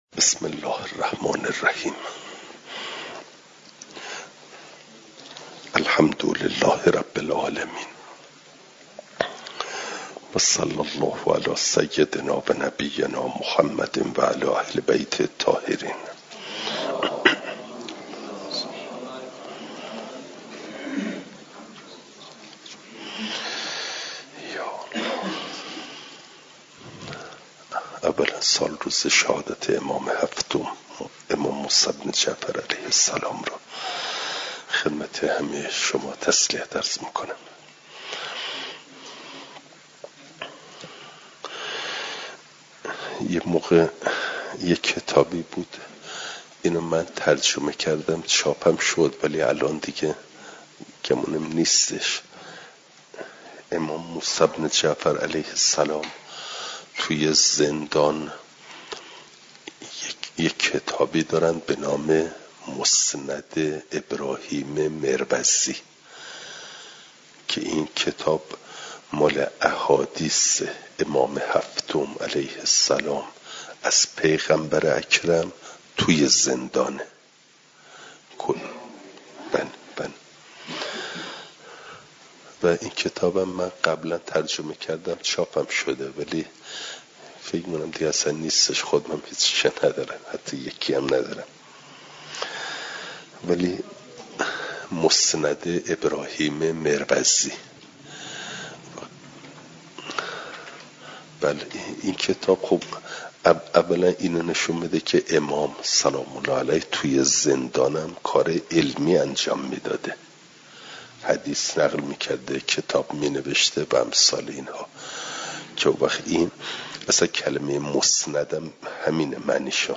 جلسه سیصد و چهلم درس تفسیر مجمع البیان